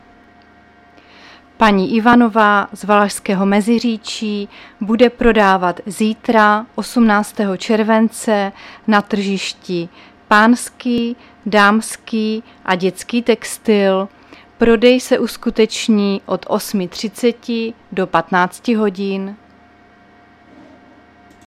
Záznam hlášení místního rozhlasu 17.7.2024
Zařazení: Rozhlas